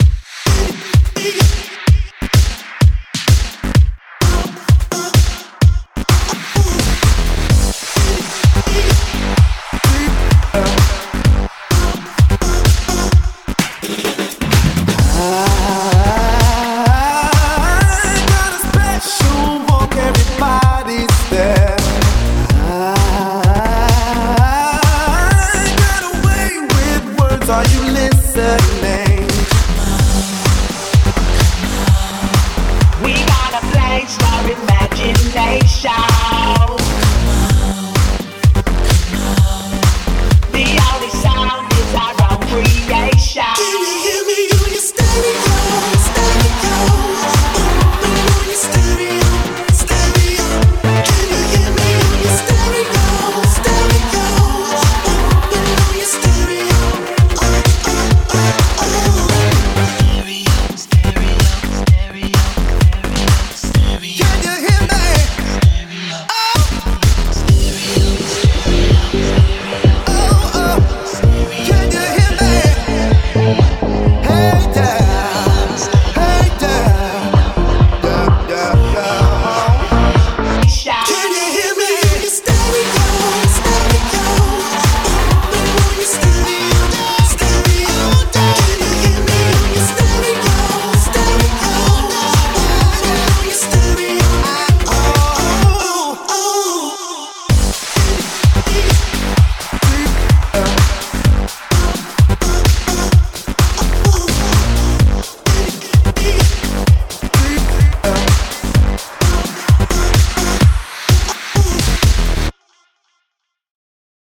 BPM128
Audio QualityMusic Cut